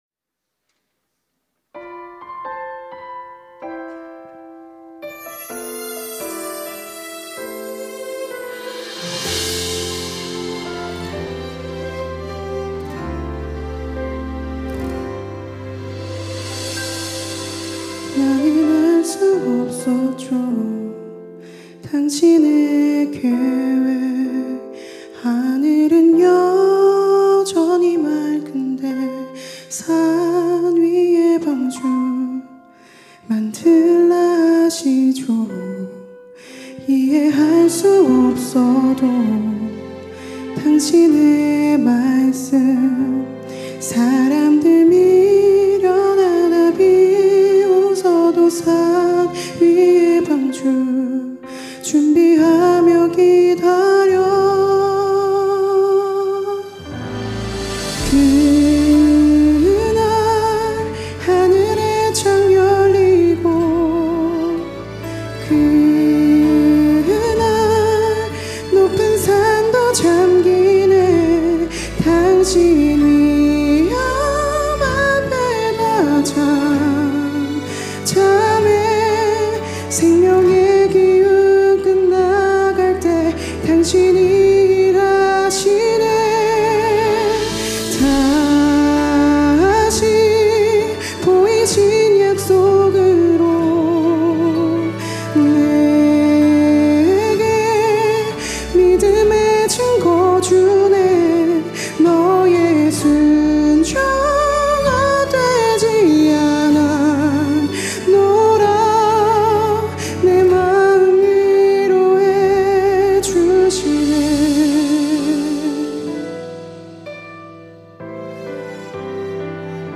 특송과 특주 - 방주